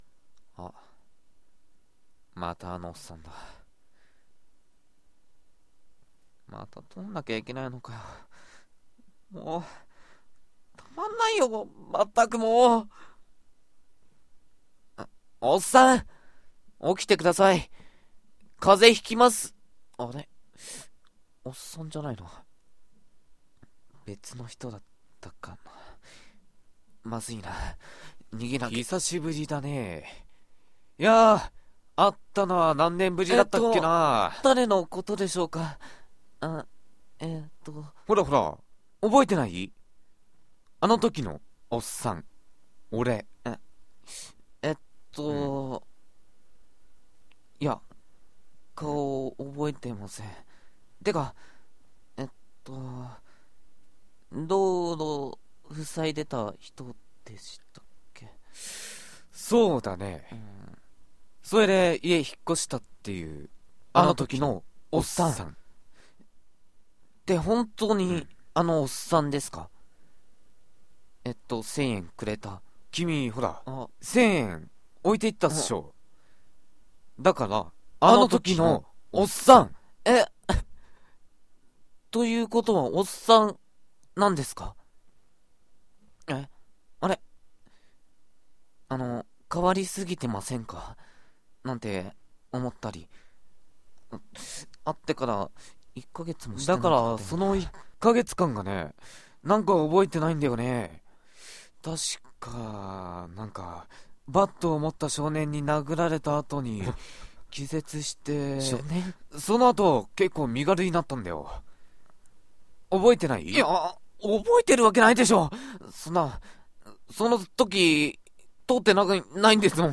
1人2役